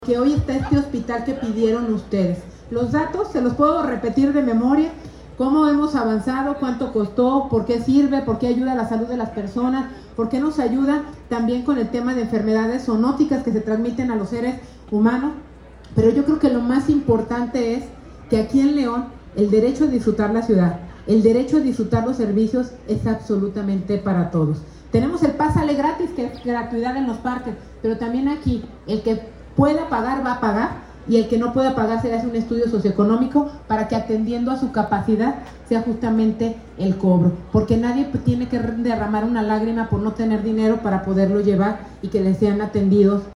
Alejandra Gutiérrez, presidenta de León